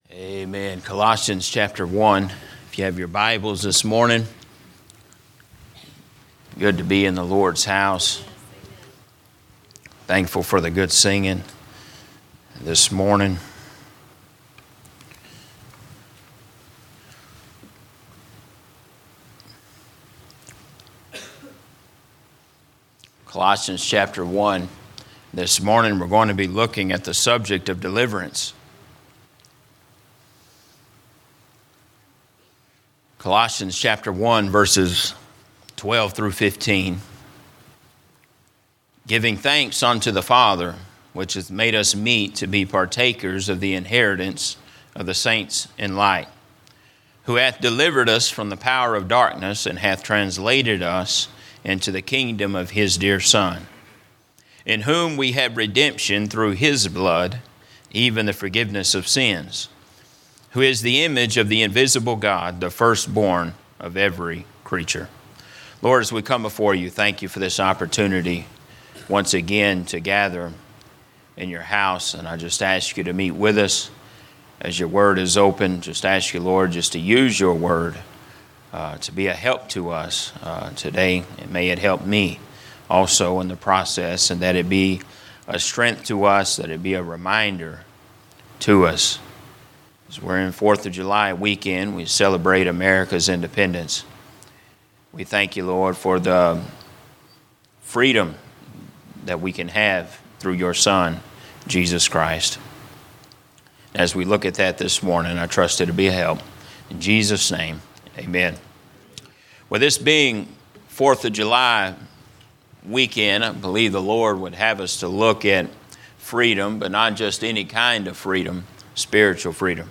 Sermons - Emmanuel Baptist Church
From Series: "General Preaching"